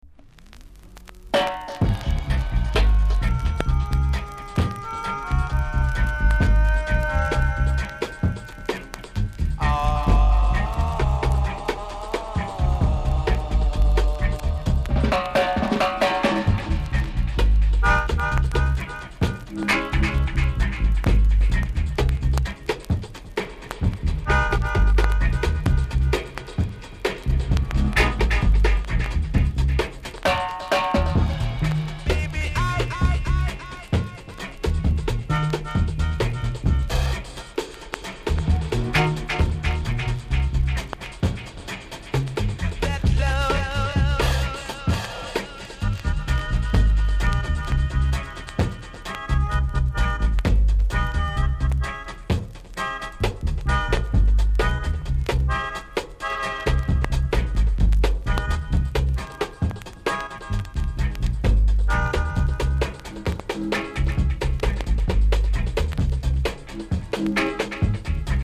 ※チリ、パチノイズが単発であります。
コメント ROCKERS CLASSIC!!